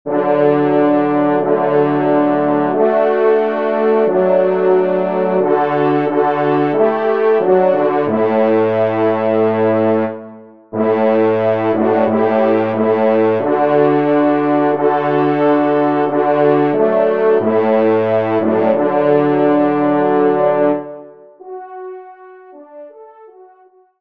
Genre : Musique Religieuse pour Trois Trompes ou Cors
Pupitre 3° Trompe